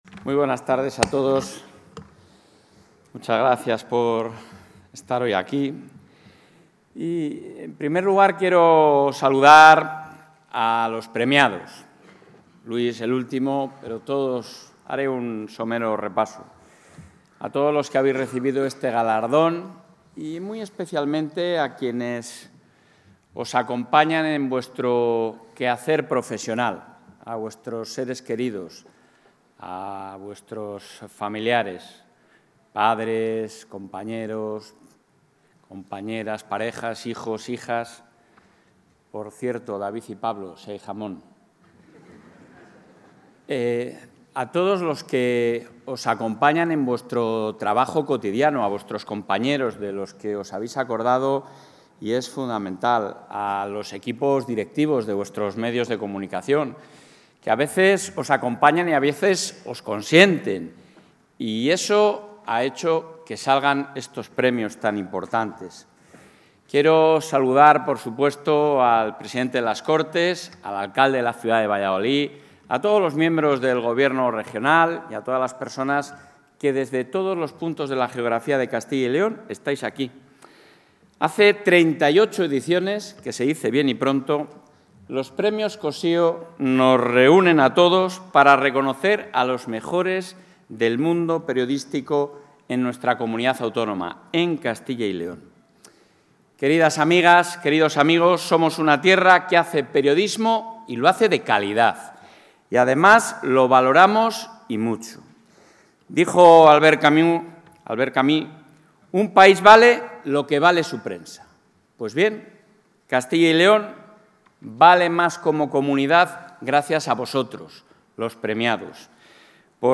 Intervención del presidente de la Junta.
El presidente de la Junta ha defendido en la gala de los XXXVIII Premios Cossío que la libertad y la pluralidad de los medios de comunicación son esenciales para la democracia y el Estado de Derecho